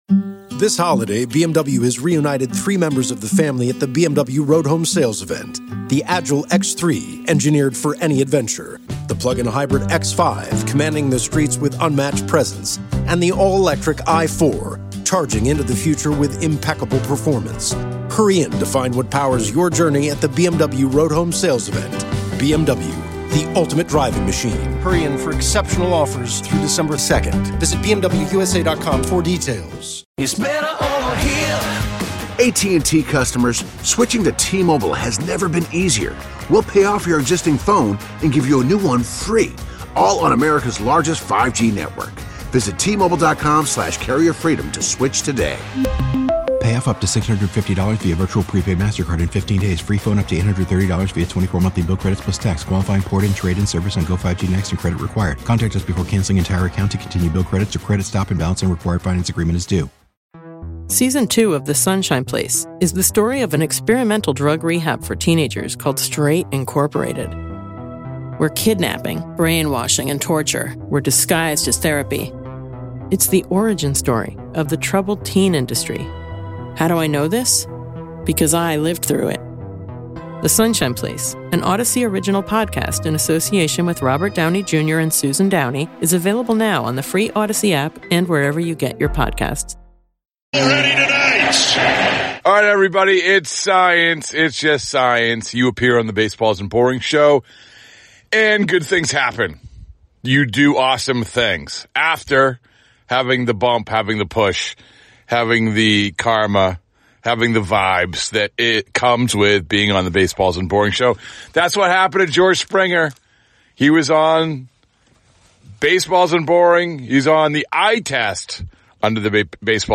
to reflect on the impact Skaggs had on the people around him Within the conversation is the story behind Giolito's tribute to Skaggs.